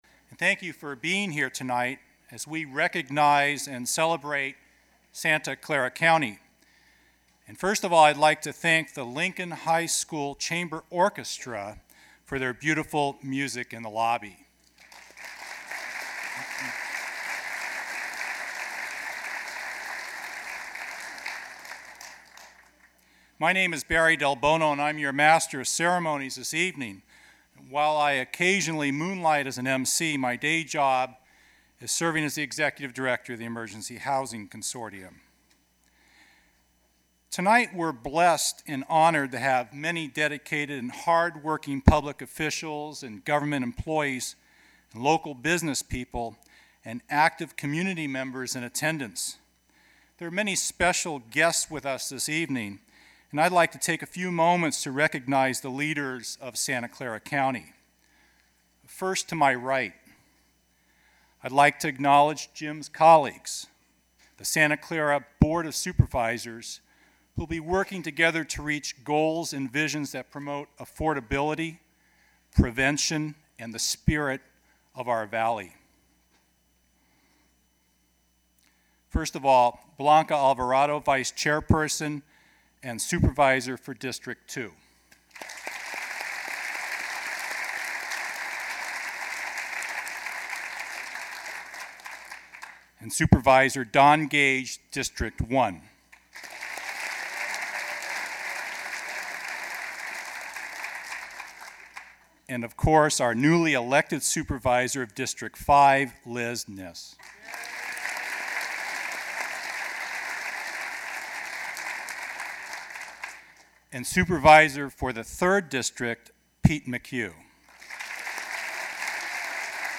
State of the County Speech, Jim Beall, January 31, 2001, Audio Recording · Archives · Santa Clara County Board of Supervisors
Audio of the State of the county address, Jim Beall, Chairman, January 31, 2001
Content Type Speech